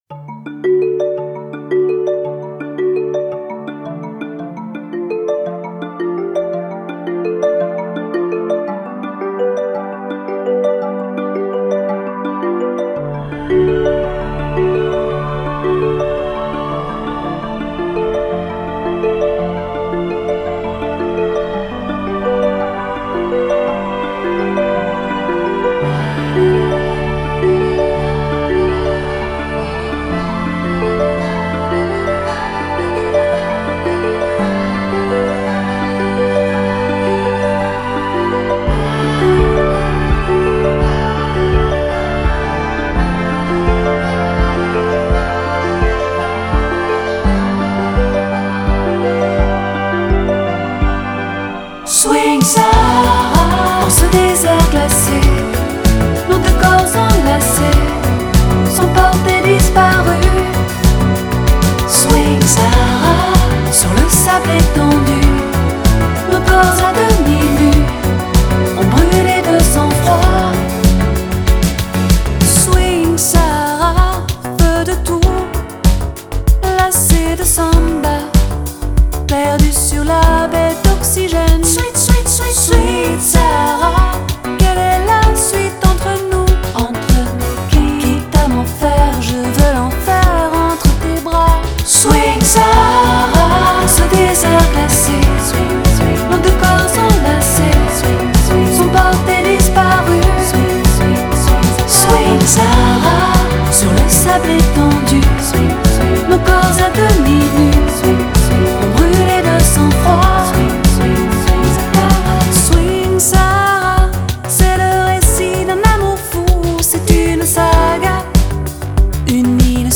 Genre: French Pop, Chanson